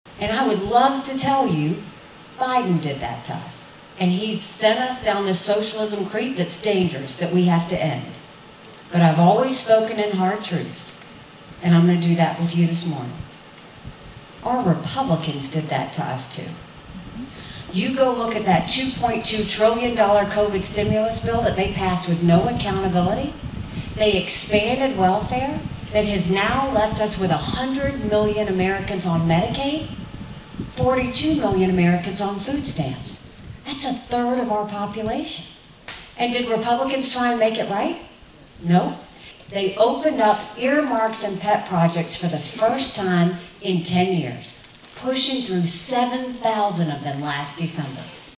Republican Presidential Candidate Nikki Haley Makes Campaign Stop in Atlantic